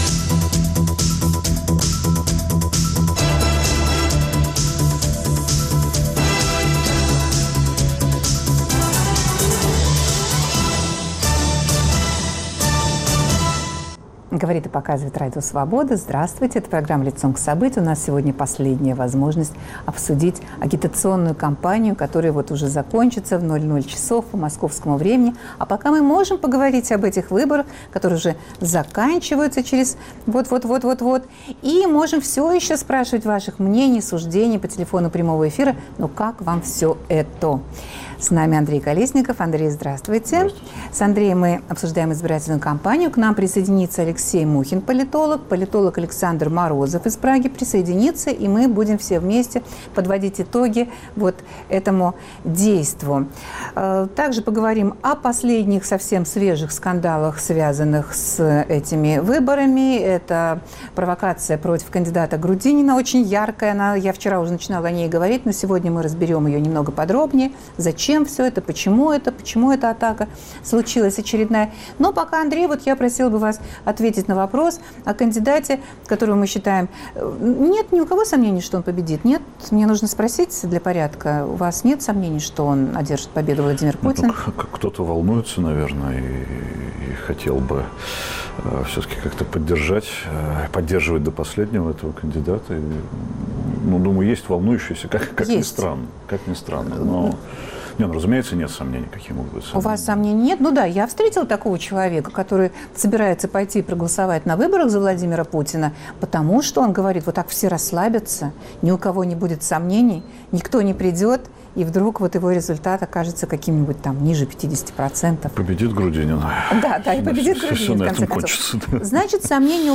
Как прошла агитационная кампания? Обсуждают политические аналитики